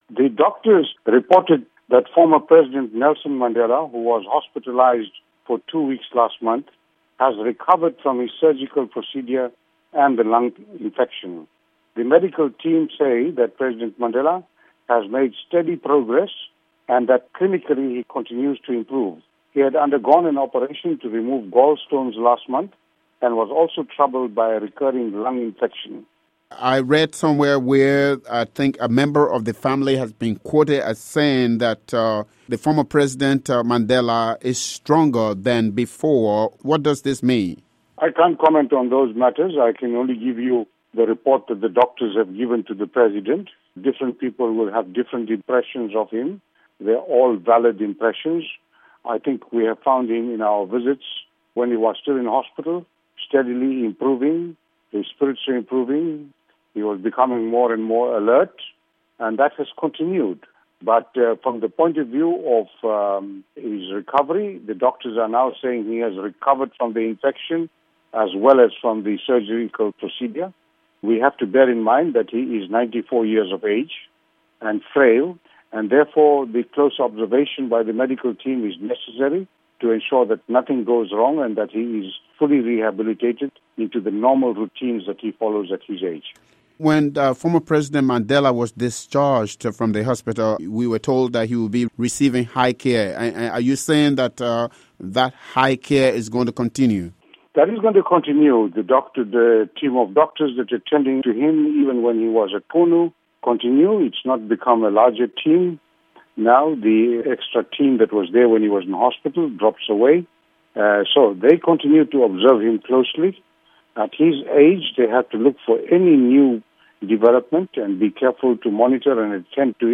Mac Maharaj, spokesman for South African President Jacob Zuma, says former president receiving ‘high care’ at Johannesburg home